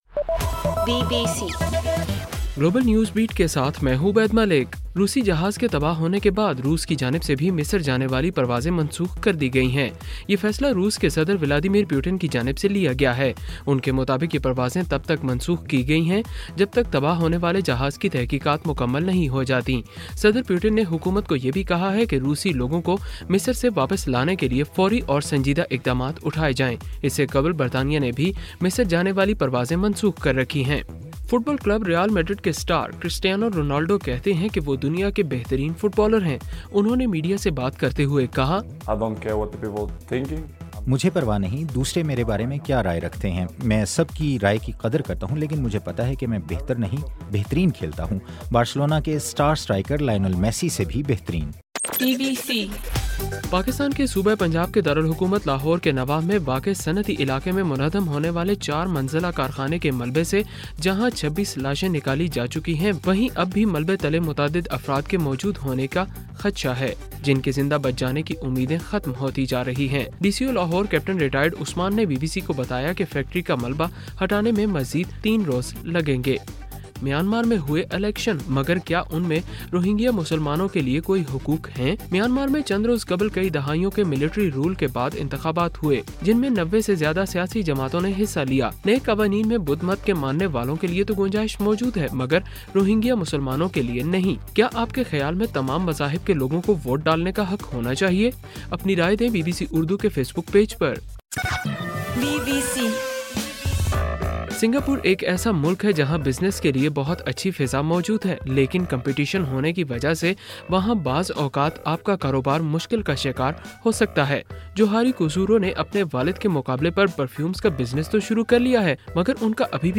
نومبر 6: رات 9 بجے کا گلوبل نیوز بیٹ بُلیٹن